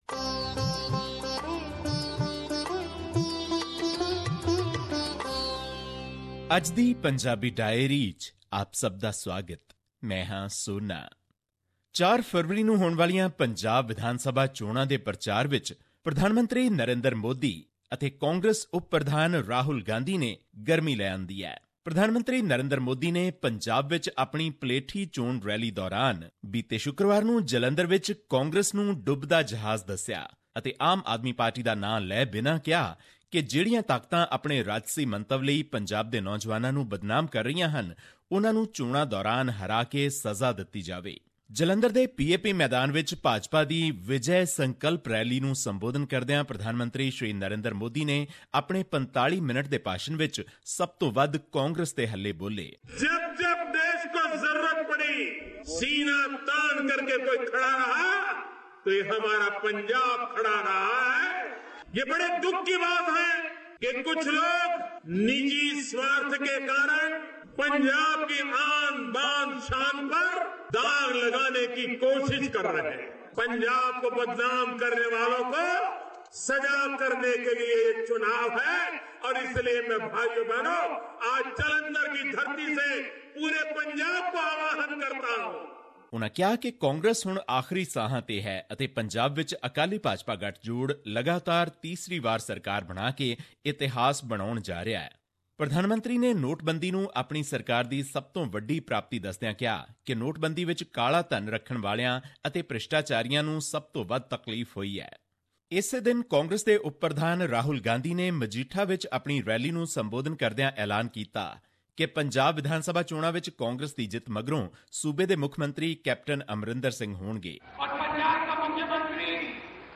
His report was presented on SBS Punjabi program on Monday, Jan 30, 2017, which touched upon issues of Punjabi and national significance in India. Here's the podcast in case you missed hearing it on the radio.